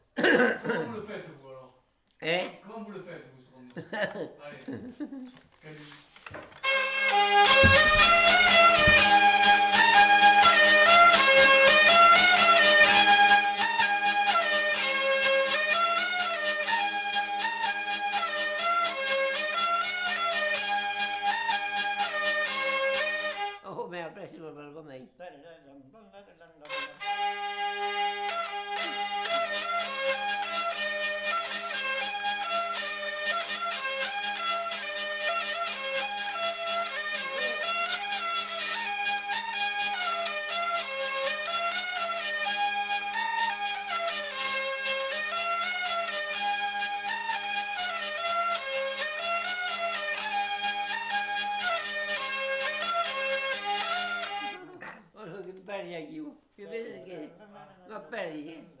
Lieu : Vielle-Soubiran
Genre : morceau instrumental
Instrument de musique : vielle à roue
Danse : rondeau